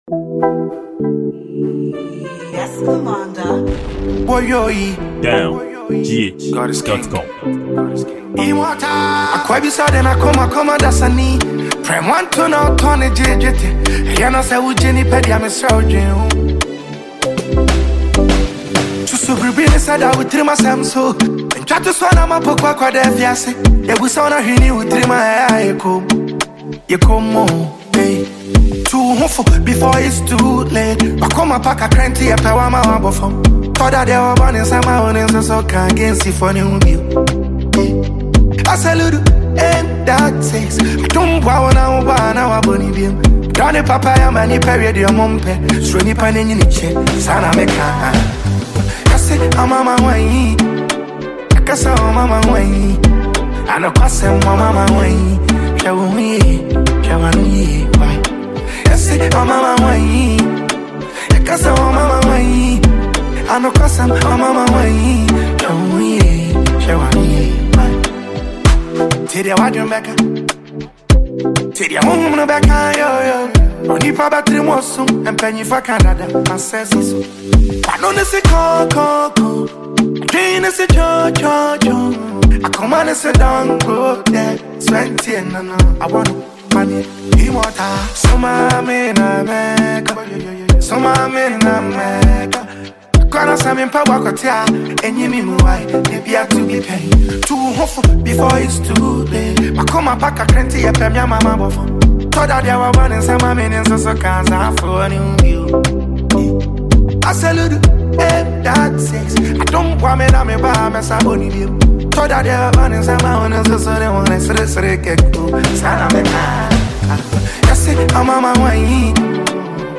Genre: Highlife